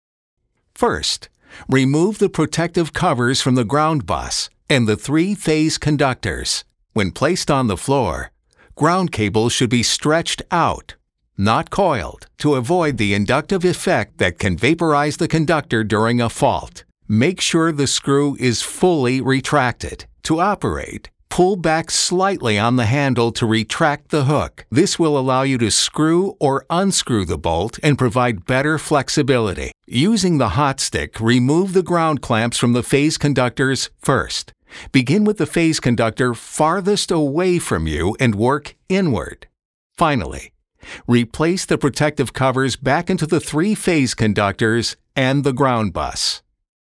E-learning-How-To-Do-Dry-no-music.mp3